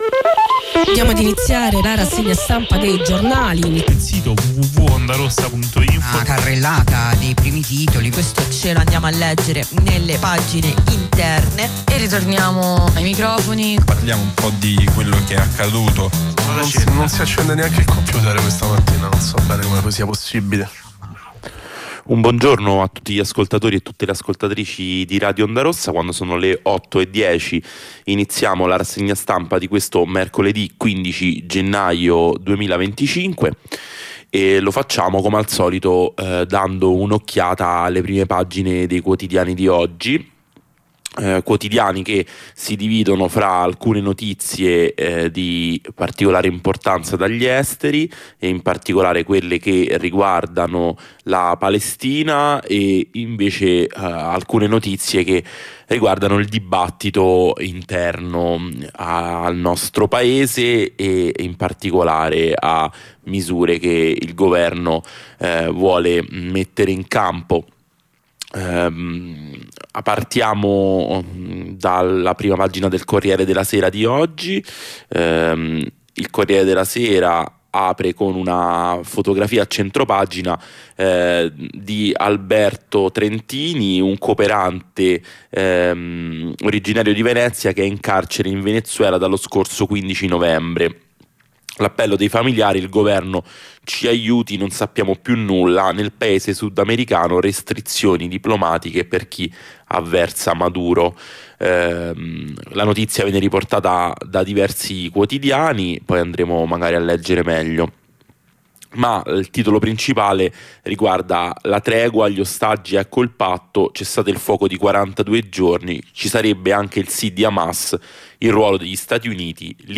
Lettura e commento dei quotidiani